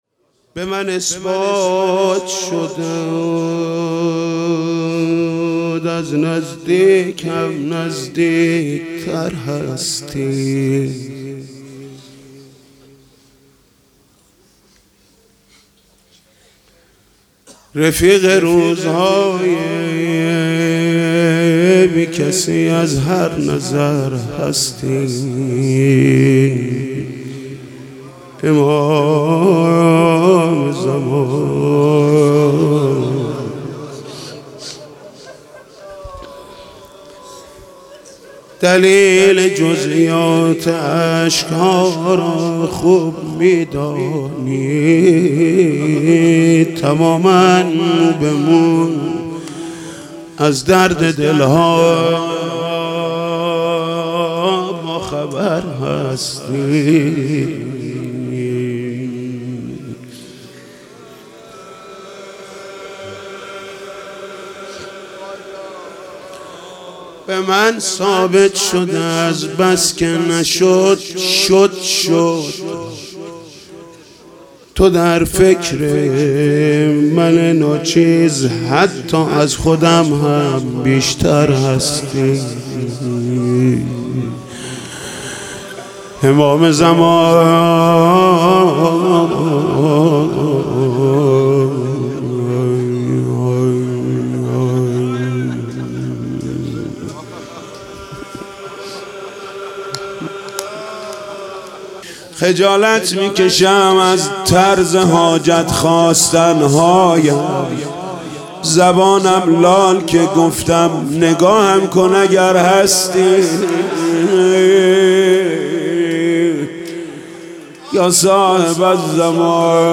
محرم96 - روضه - به من اثبات شد از نزدیک هم نزدیکتر هستی
شب سوم محرم - به نام نامیِ حضرت رقیه(س)